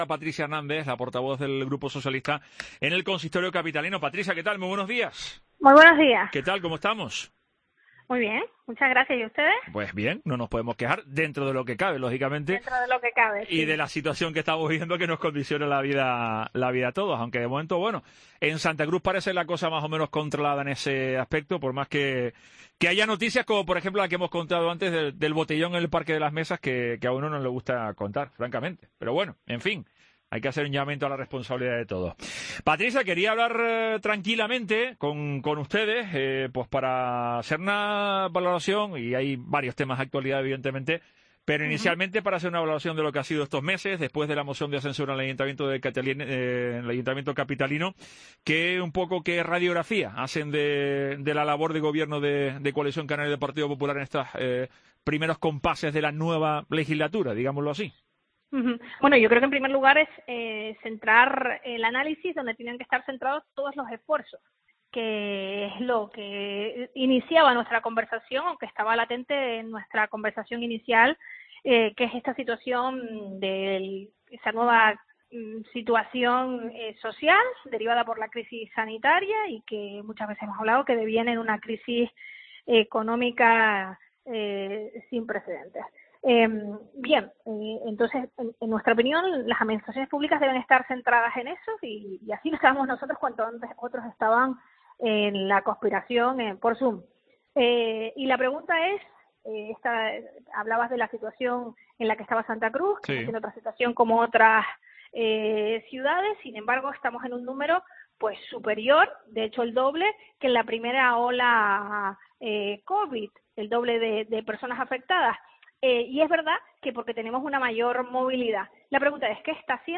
La portavoz del grupo socialista en el Ayuntamiento de Santa Cruz y ex alcaldesa Patricia Hernández ha pasado hoy por nuestro programa La Mañana de COPE Tenerife para hacer un repaso a los primeros meses de gestión por parte del nuevo equipo de gobierno en el consistorio.